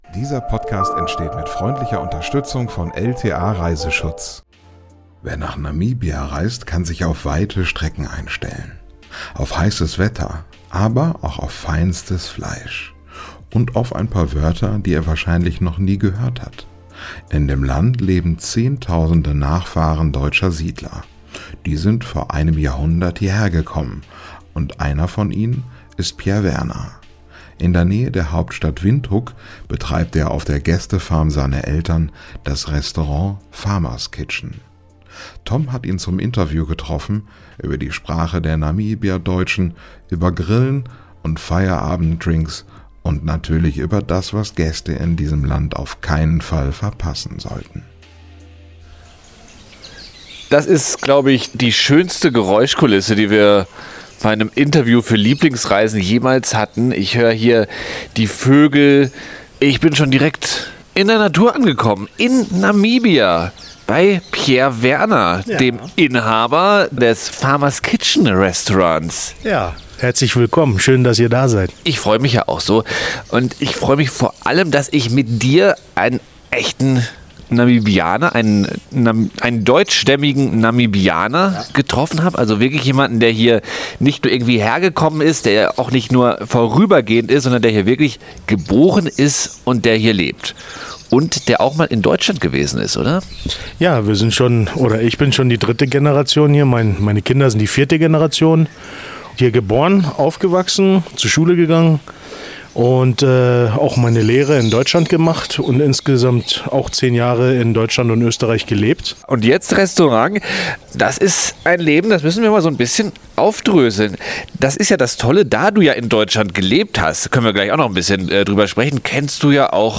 Interview
Wenn du den Podcast mit dem Kopfhörer hörst, dann gehst du mit in 3D-Audio auf Reisen: Übernachte im Zelt, während es um dich rum schüttet, komm mit auf eine Yoga-Session im Allgäu oder besuche die belebten Souks von Marrakesch.